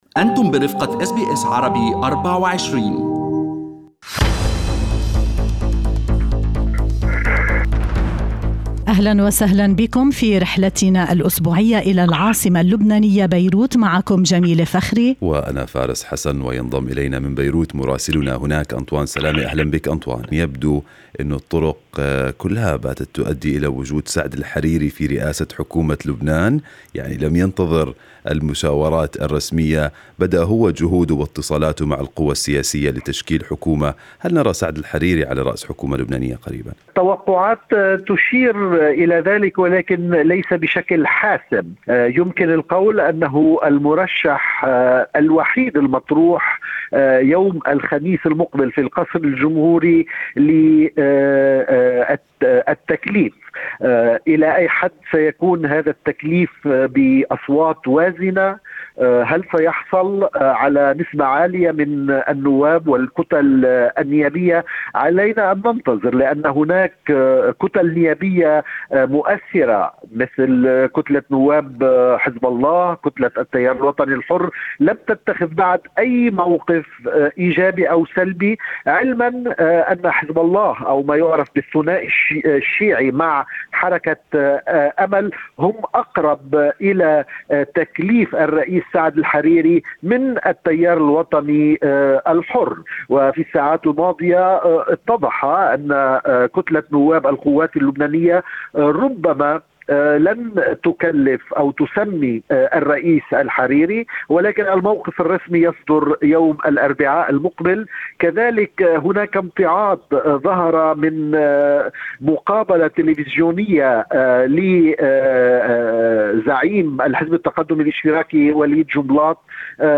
من مراسلينا: أخبار لبنان في أسبوع 13/10/2020